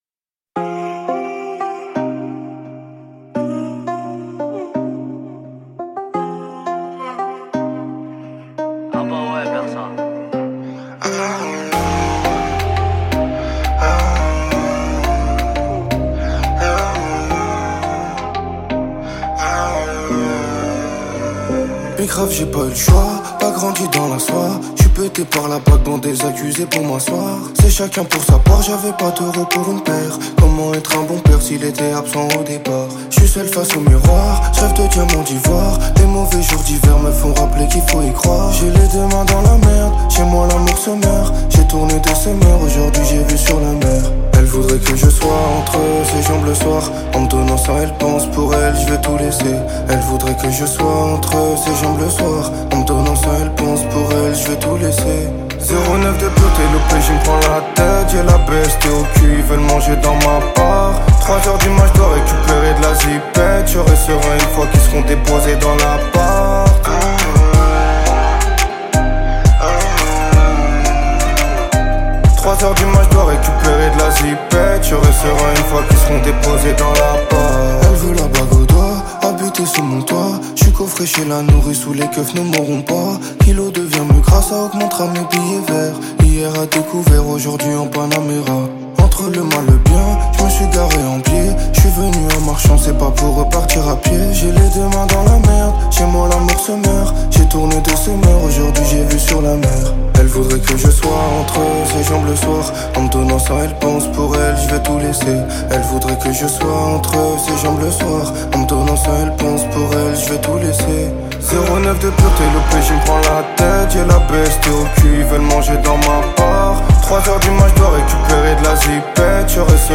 54/100 Genres : french rap, pop urbaine Télécharger